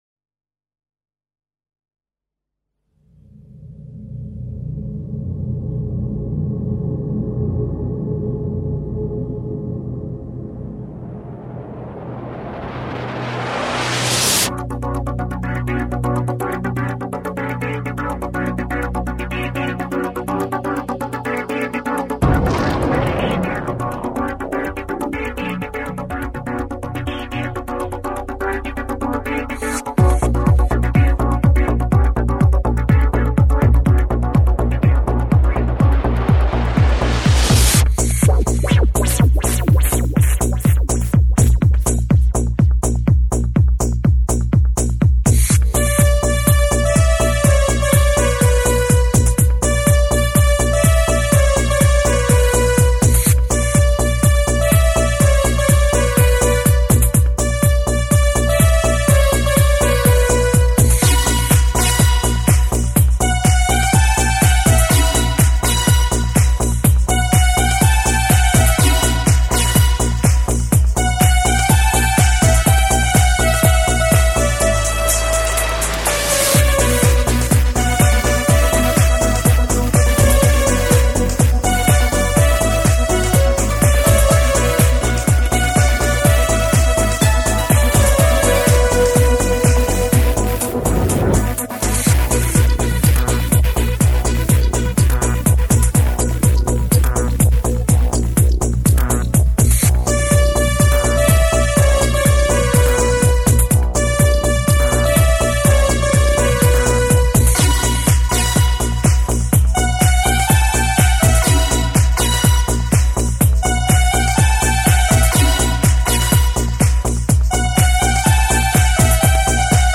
Best Instrumental